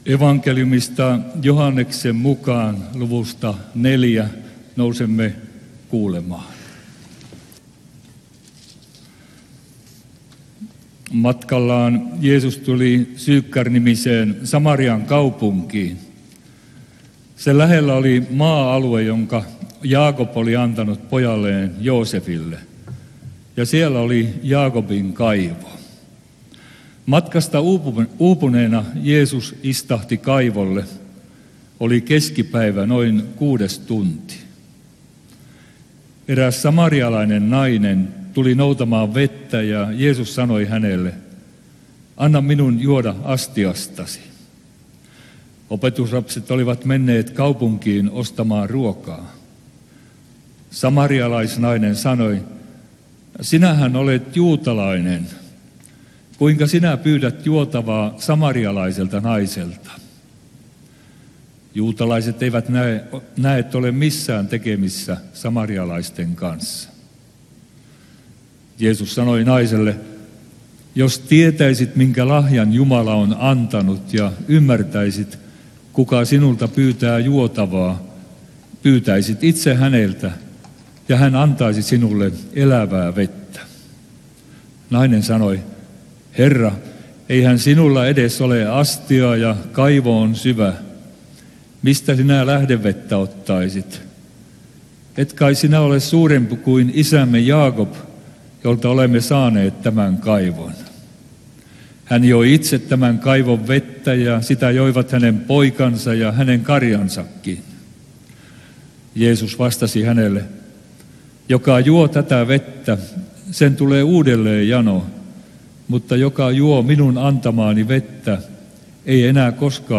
saarna Toholammilla 2. sunnuntaina loppiaisesta